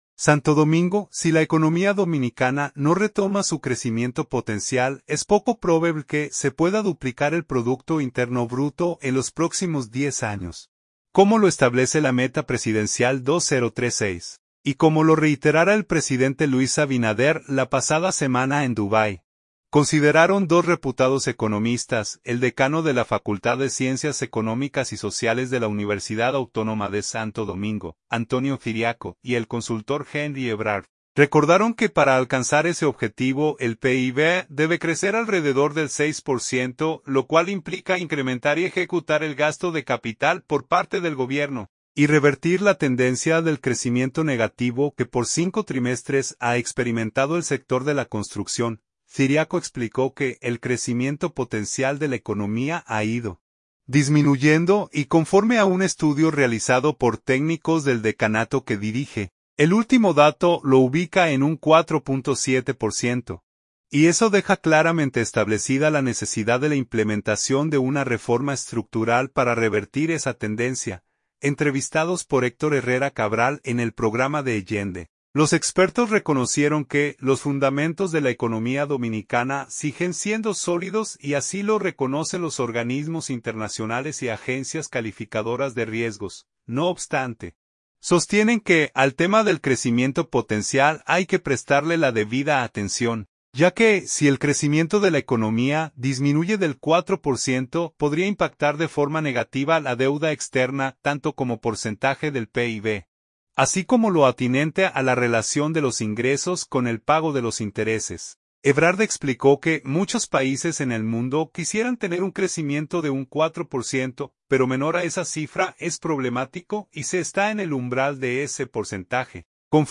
Entrevistados